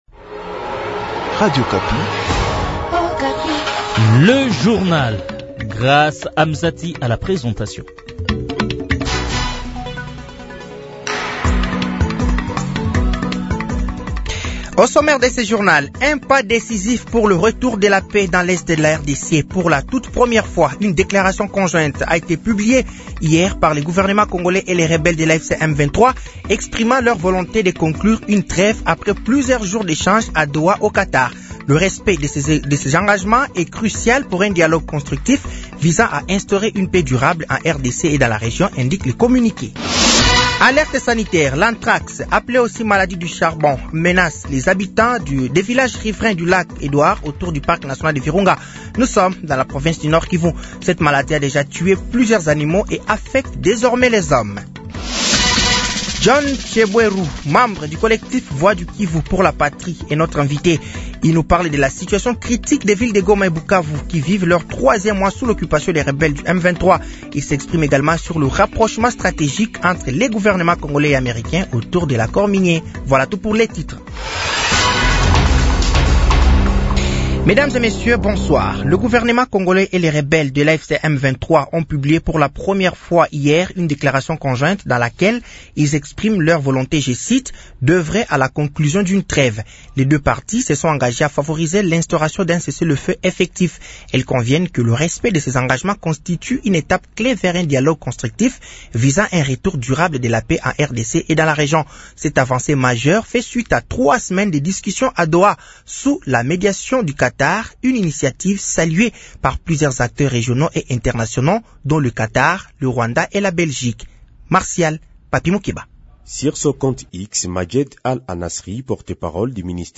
Journal français de 18h de ce jeudi 24 avril 2025